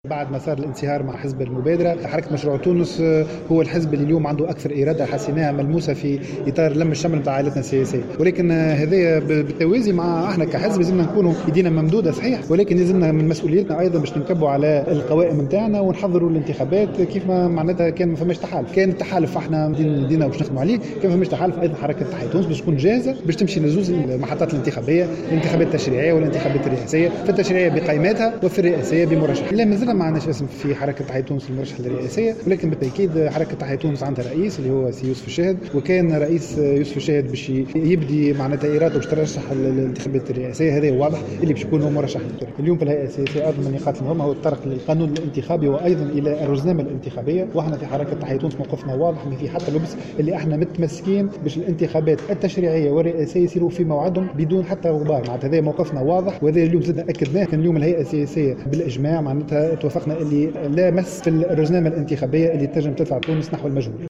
أكد الأمين العام لحركة تحيا تونس سليم العزابي في تصريح لمراسلة الجوهرة "اف ام" أن الحركة ستدخل الانتخابات التشريعية بقائماتها الخاصة.